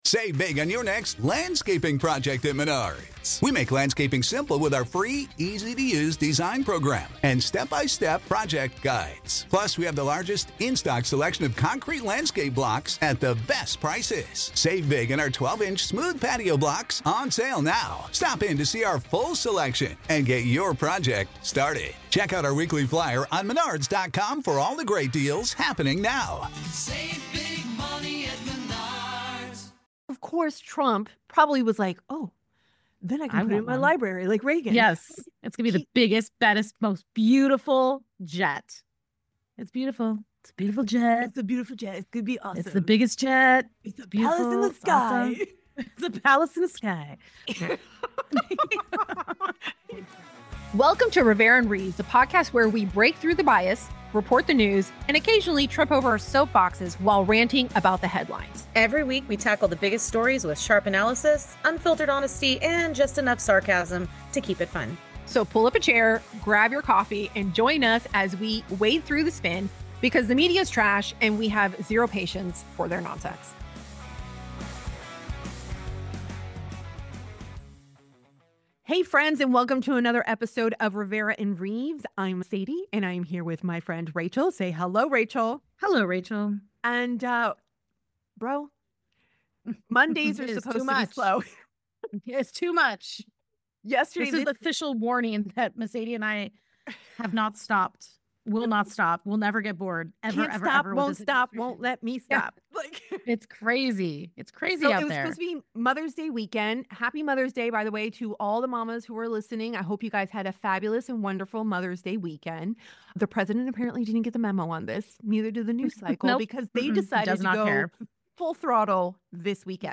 It's time for another dose of news, analysis and commentary